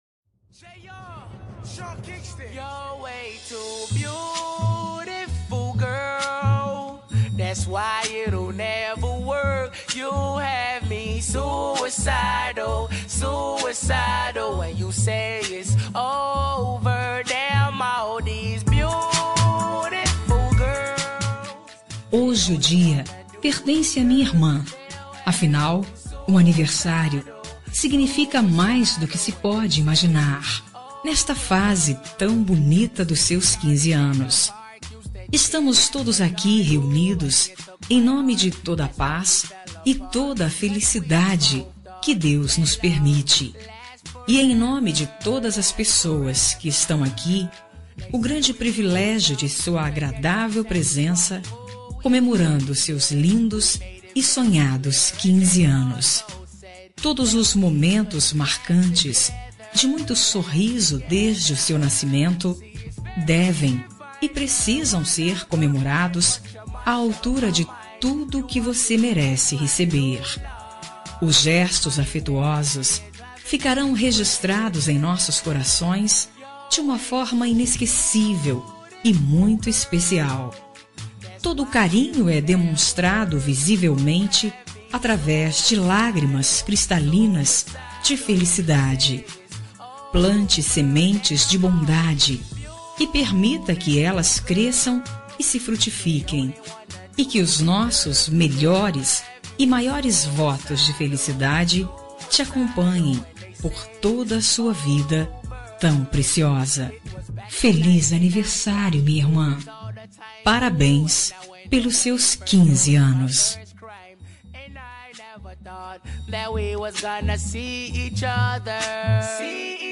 Aniversário de 15 anos – Voz Feminina – Cód: 33370 – Irmã